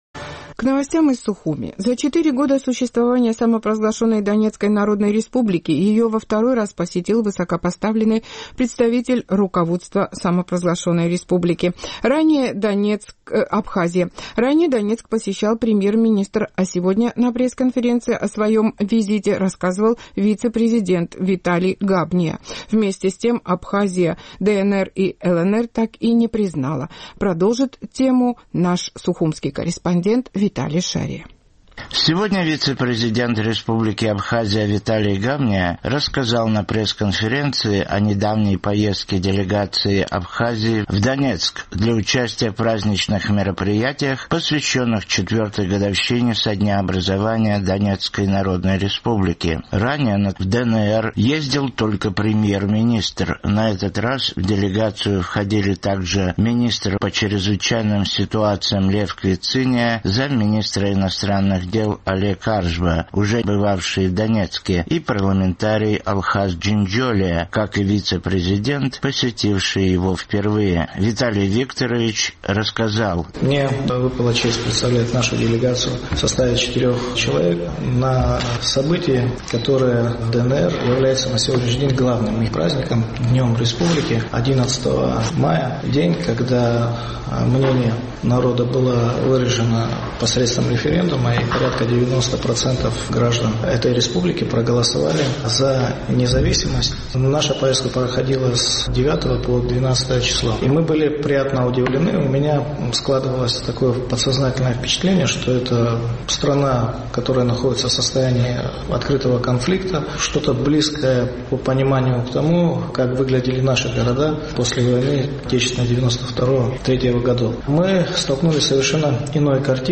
За четыре года существования Донецкой Народной Республики ее во второй раз посетил высокопоставленный представитель руководства Абхазии. Ранее Донецк посещал премьер-министр, а сегодня на пресс-конференции о своем визите рассказывал вице-президент Виталия Габния.